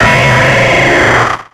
Cri de Linéon dans Pokémon X et Y.